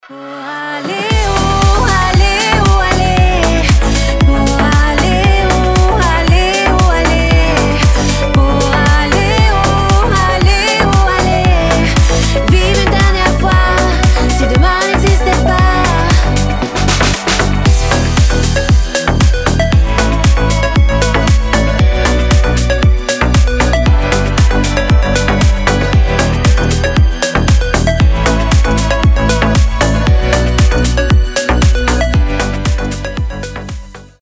• Качество: 320, Stereo
deep house
женский голос